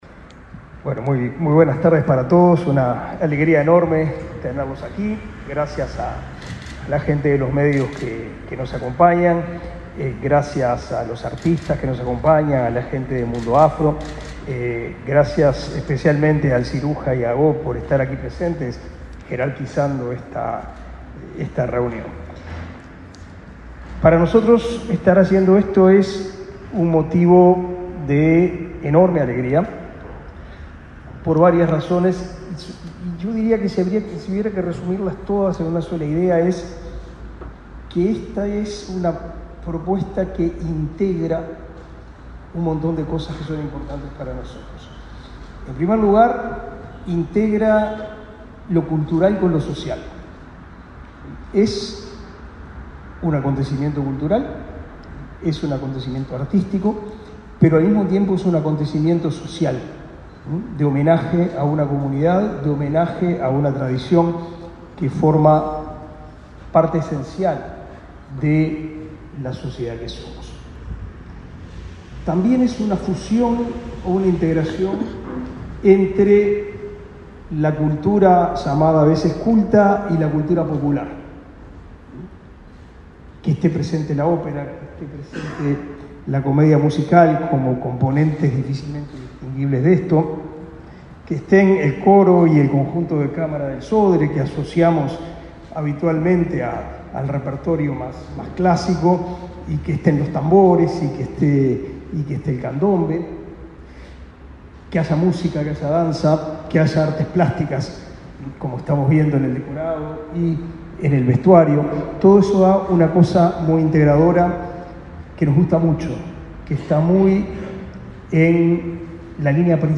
Palabras del ministro de Educación y Cultura, Pablo da Silveira
El ministro de Educación y Cultura, Pablo da Silveira, asistió, este 7 de junio, al lanzamiento de primera ópera de la temporada 2023, La perla negra,